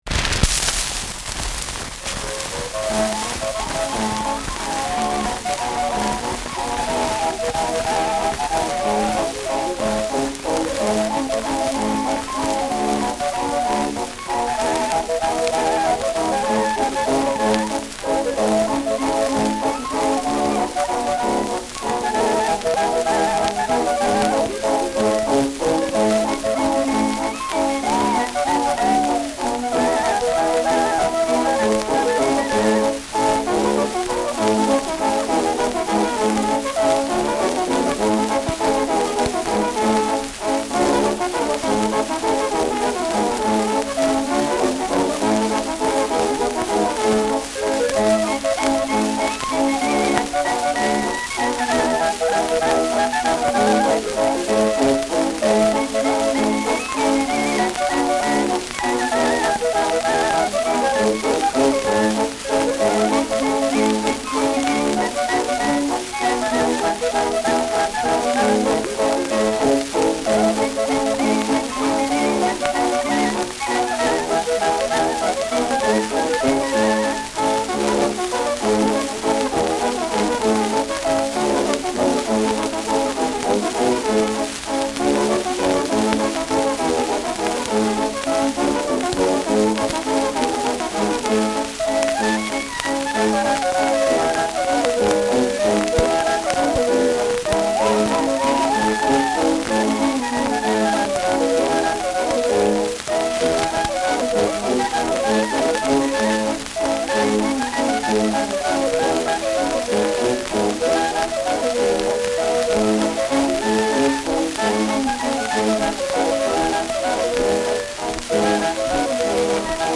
Schellackplatte
Stark abgespielt : Starkes Grundrauschen : Vereinzelt leichtes Knacken
[Nürnberg] (Aufnahmeort)